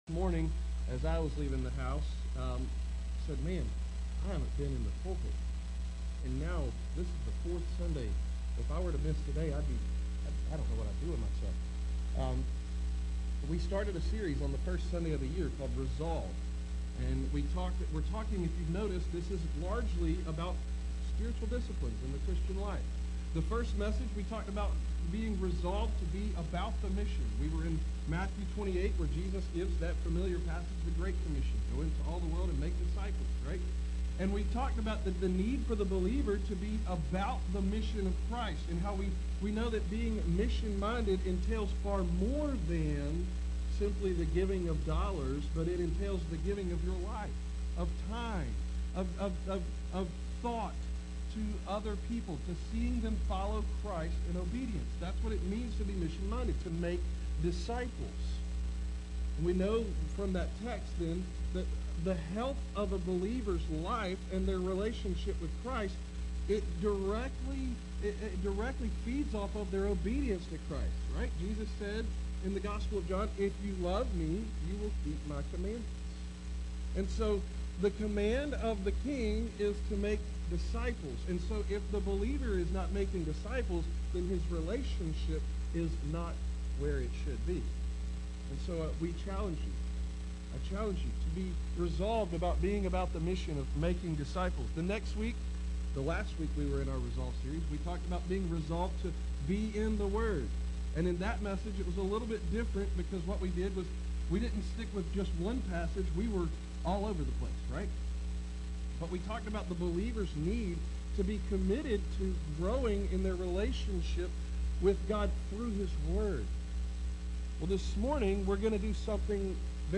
**- Please pardon the audio quality on this message.